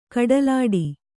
♪ kaḍalāḍi